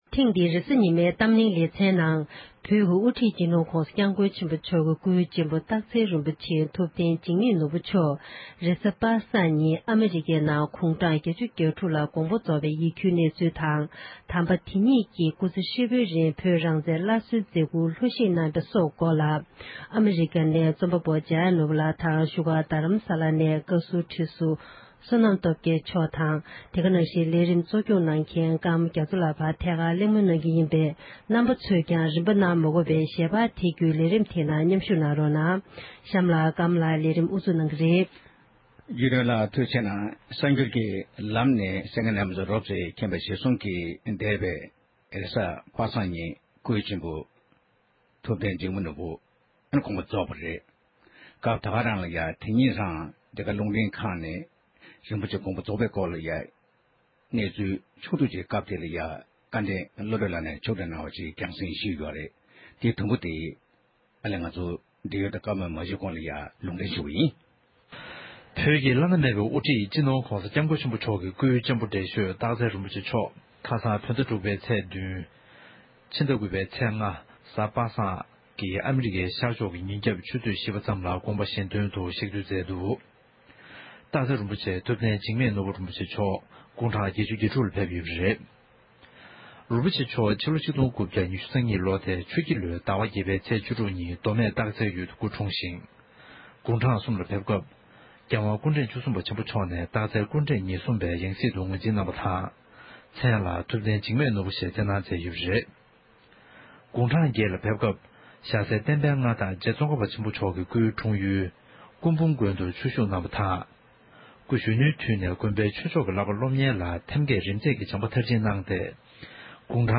བགྲོ་གླེང་གནང་བར་གསན་རོགས་གནང༌༎